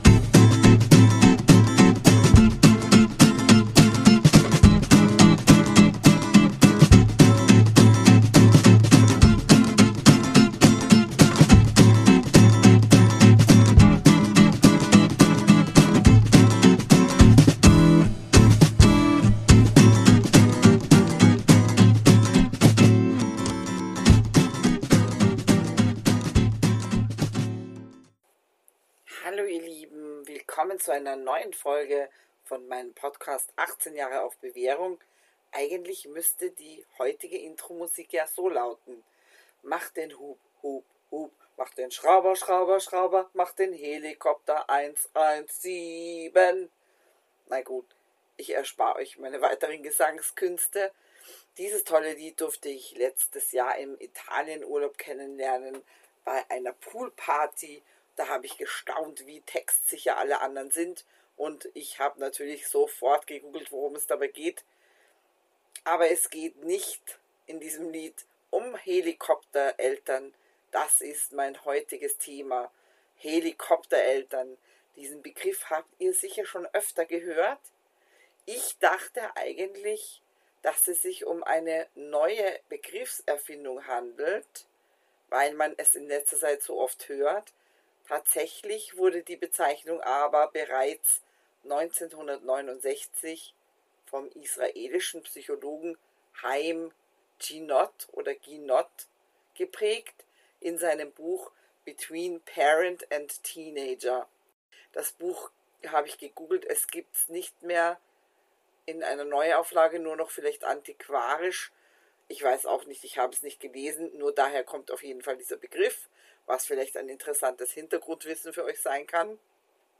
In dieser Folge beschäftige ich mich mit dem Thema und gebe euch Tipps, wie ihr aus dem Teufelskreis herausfindet. Bitte entschuldigt die nicht optimale Qualität, weiß auch nicht, was da los war...